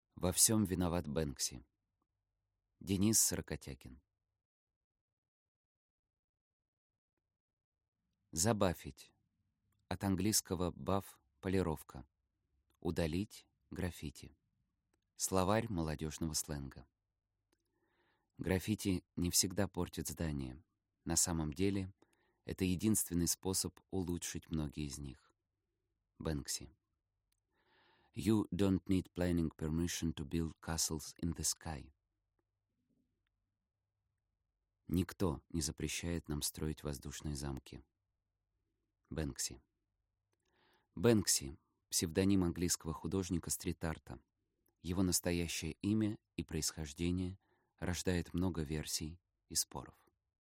Аудиокнига Во всем виноват Бэнкси | Библиотека аудиокниг